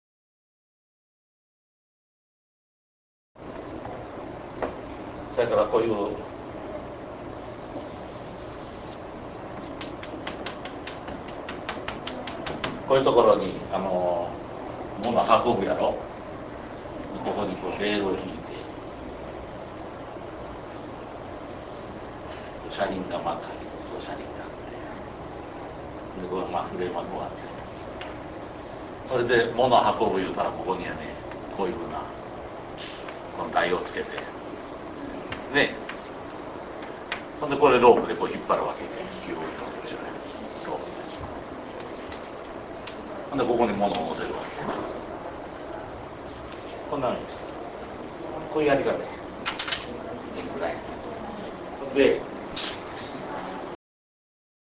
インタビュー ビデオをご覧いただくには、RealPlayerソフトが必要です。